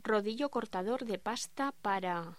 Locución: Rodillo cortador de pasta para...
voz
Sonidos: Voz humana